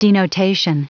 Prononciation du mot denotation en anglais (fichier audio)
Prononciation du mot : denotation